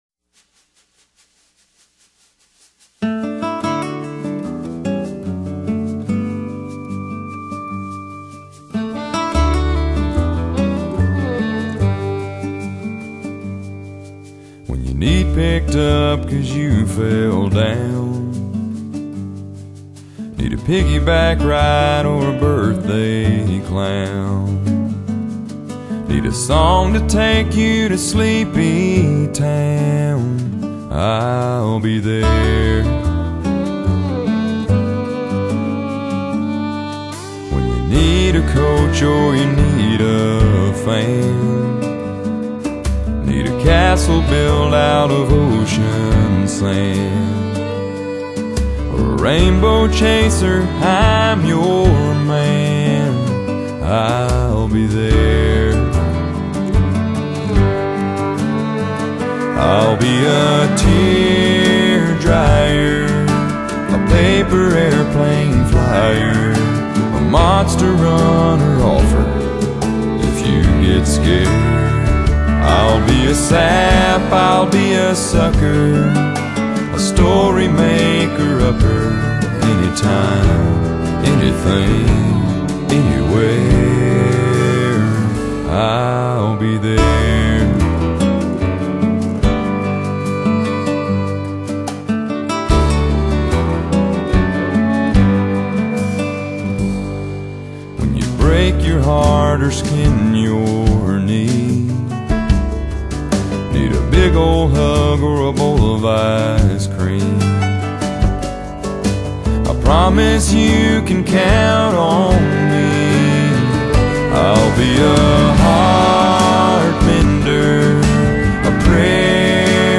Genre : Country
他擁有令人讚嘆的超齡嗓音與突破樂界籓籬的超級魅力